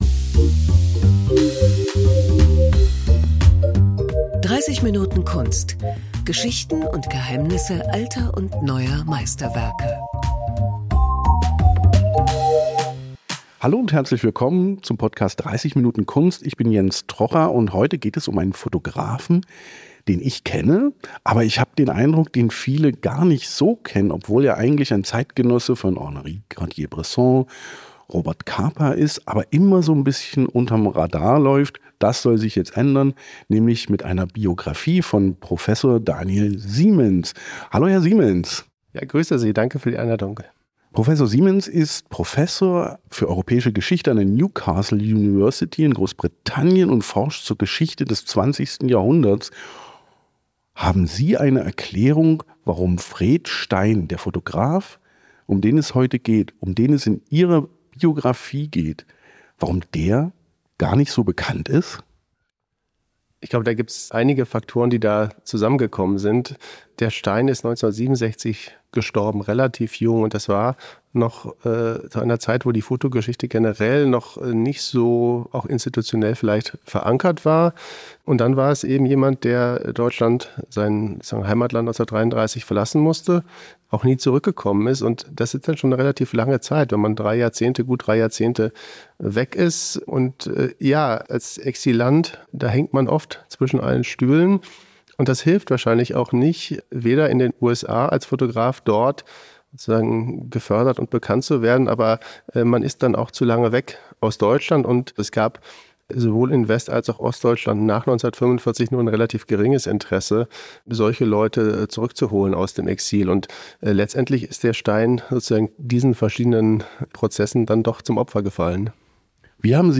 Fred Stein ist der Fotograf hinter einigen der bekanntesten Bilder des 20. Jahrhunderts – und doch blieb er selbst lange unbekannt. Im Gespräch